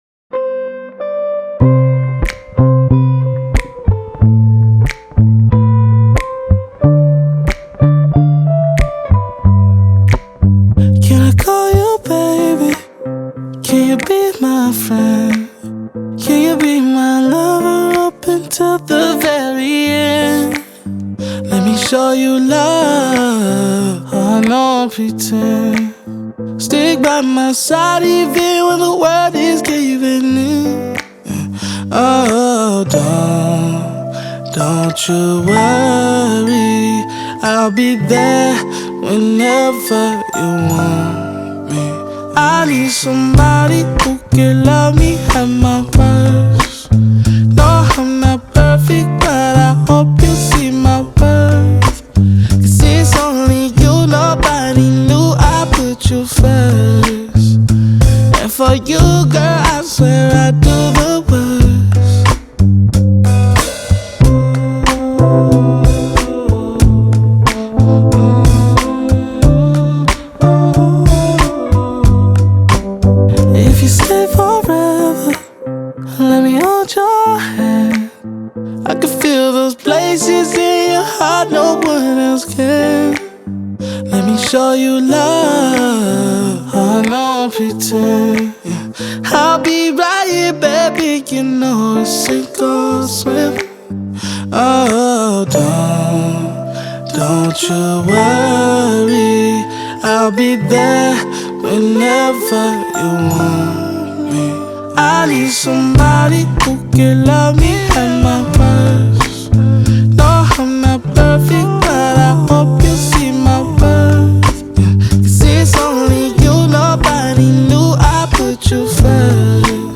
American RnB Sensational musician